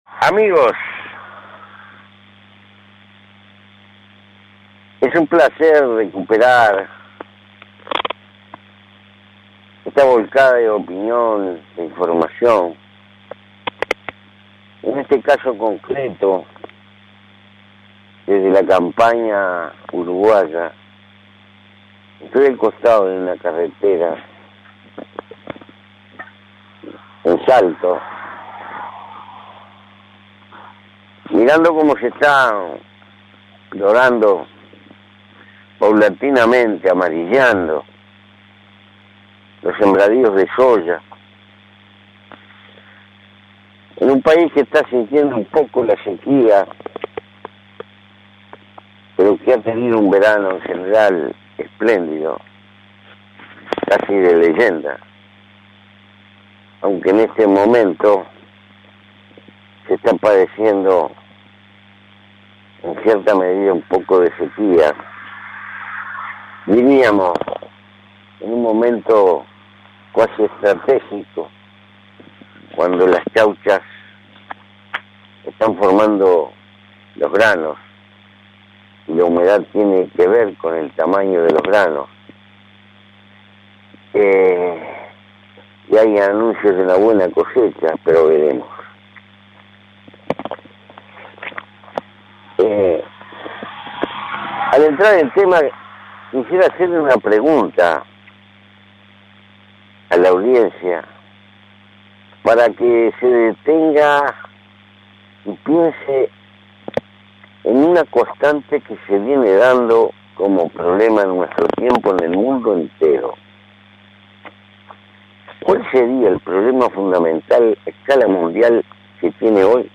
Audición Mujica